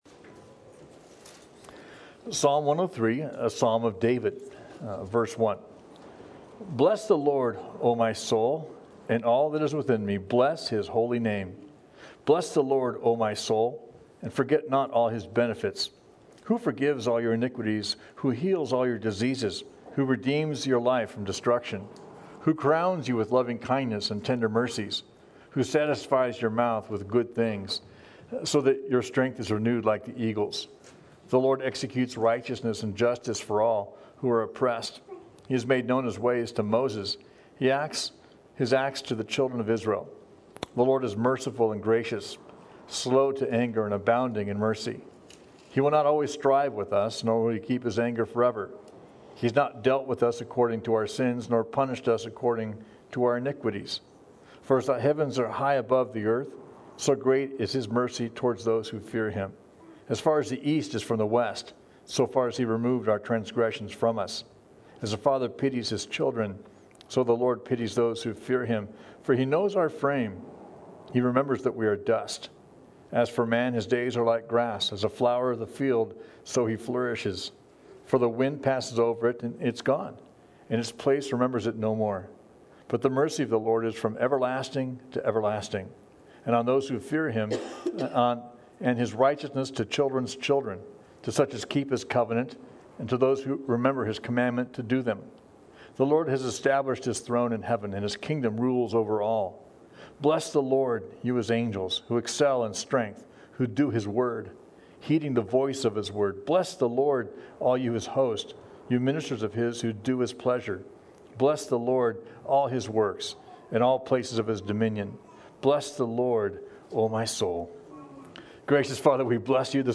We livestream all of our services on FaceBook.